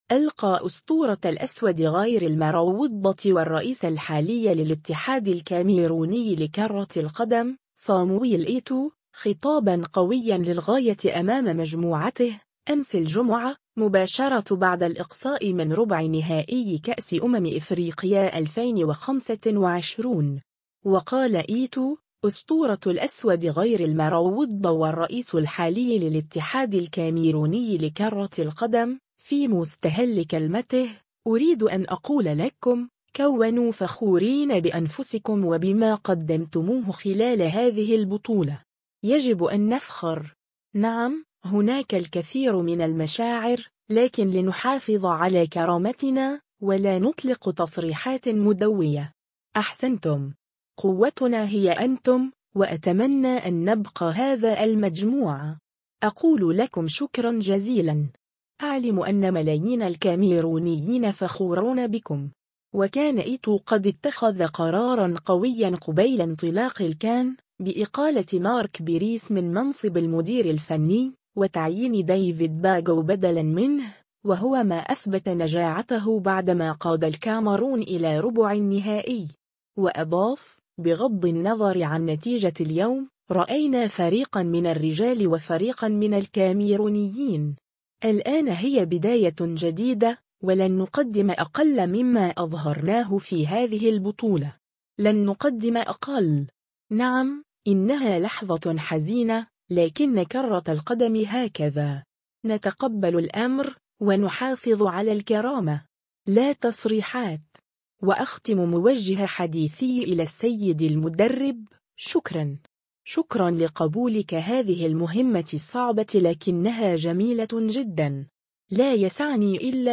ألقى أسطورة «الأسود غير المروّضة» والرئيس الحالي للاتحاد الكاميروني لكرة القدم، صامويل إيتو، خطابًا قويًا للغاية أمام مجموعته، أمس الجمعة، مباشرة بعد الإقصاء من ربع نهائي كأس أمم إفريقيا 2025.
و في ختام هذه اللحظة العاطفية، التفّ برايان مبويمو و زملاؤه حول المهاجم السابق لبرشلونة و إنتر ميلان، مردّدين النشيد الوطني.